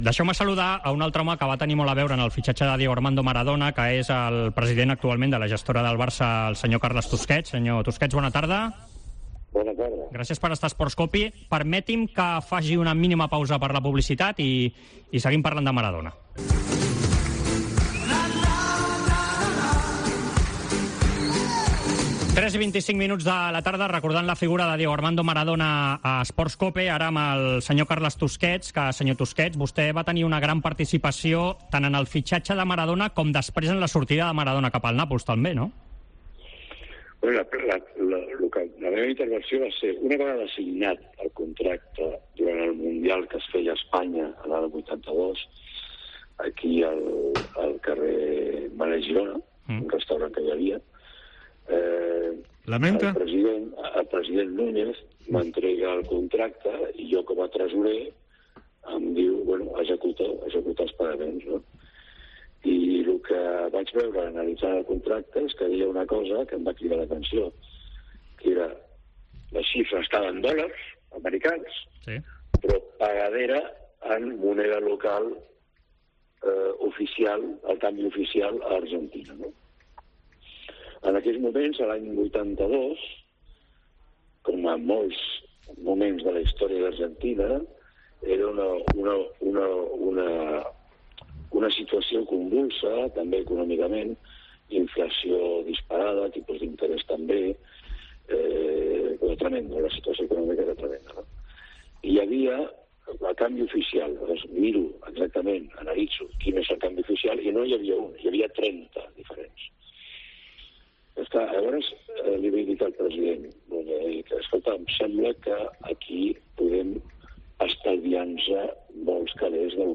AUDIO: Escolta la conversa amb el president de la Comissió Gestora recordant la seva intervenció en el fitxatge de l'argentí l'any 1982.